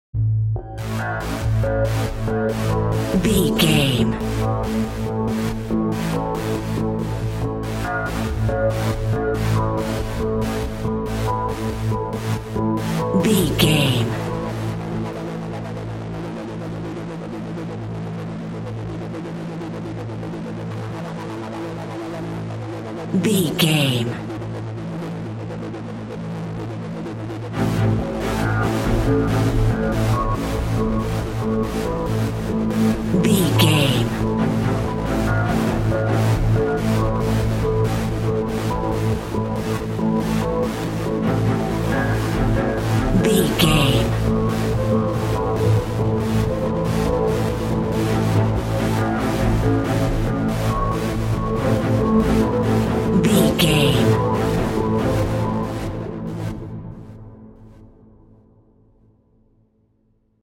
Survival horror
Aeolian/Minor
Fast
synthesiser